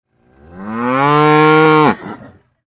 دانلود صدای نعره گاو عصبانی و خشمگین از ساعد نیوز با لینک مستقیم و کیفیت بالا
جلوه های صوتی